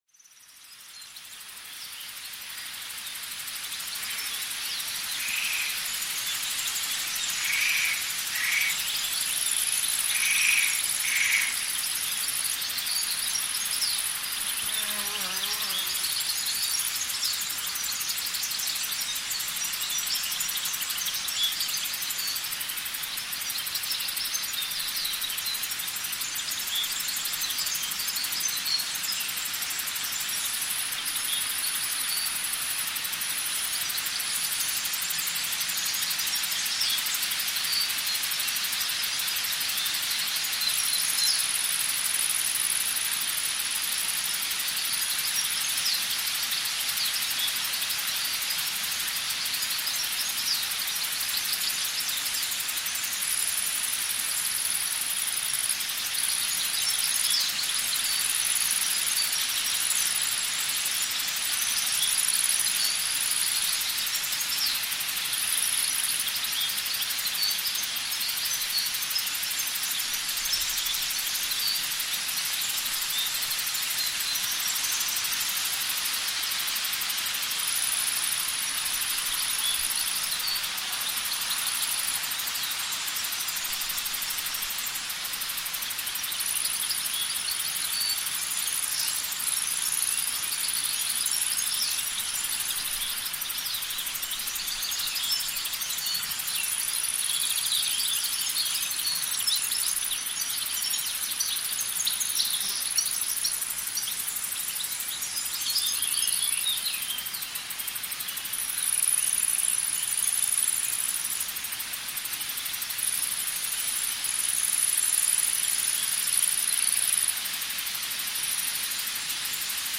Sommermorgen-Moor: Sanfte Brise + Vogelrufe = perfekte Entspannung